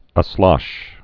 (ə-slŏsh)